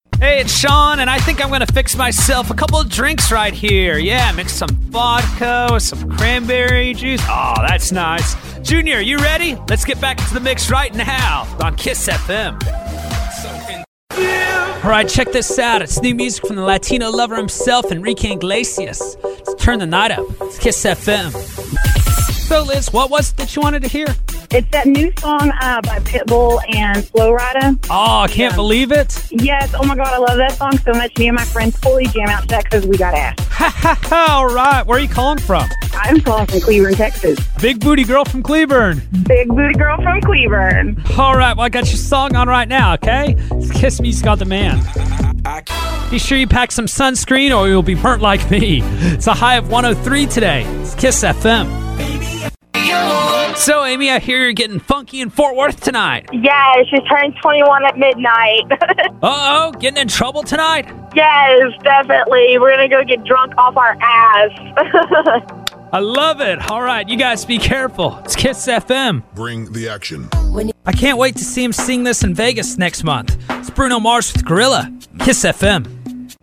On-Air Demos: